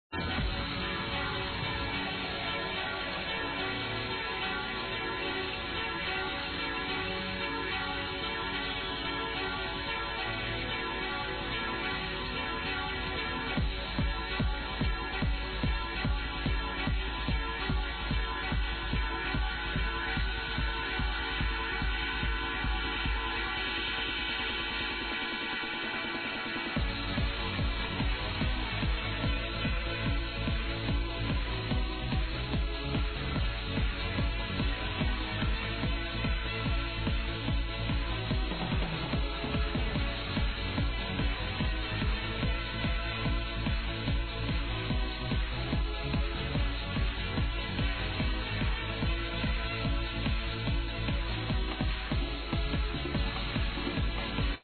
I can't hear it very well